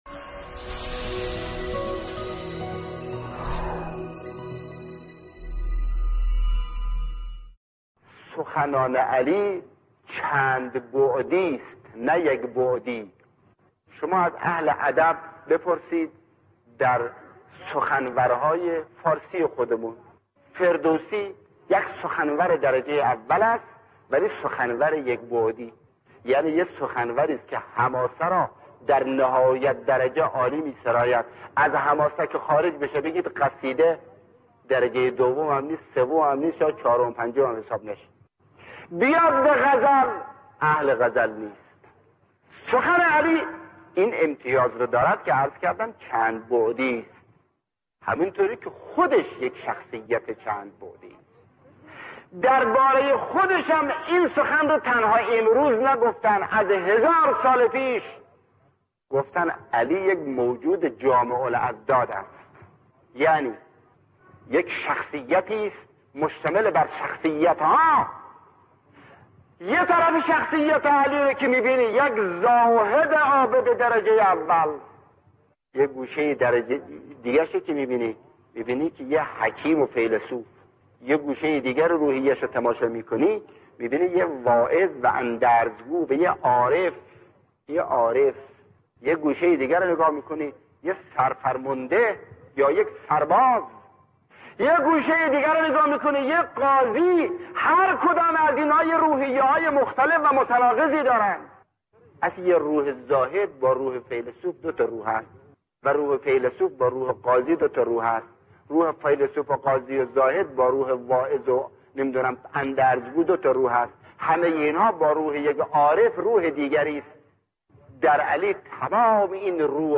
فیلم/ گزیده‌ای از سخنرانی معلم شهید «مرتضی مطهری»